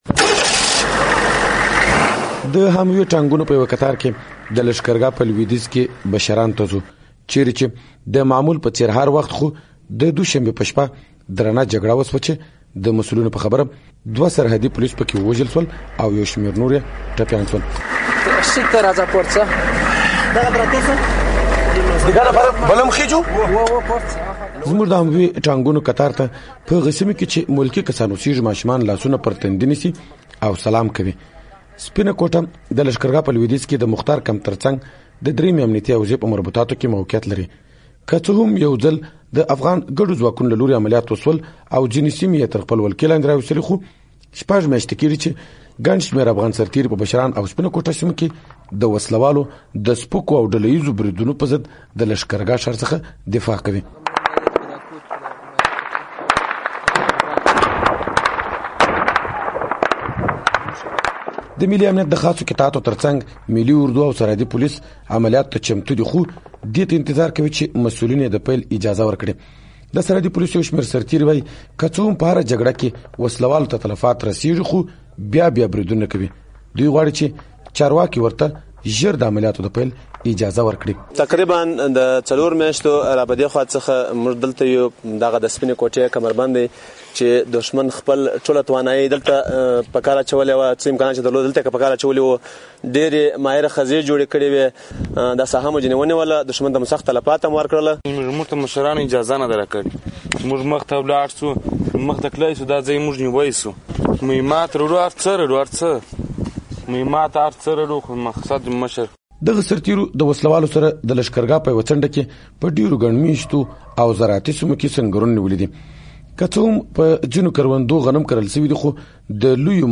نور حال په لاندې راپور کې: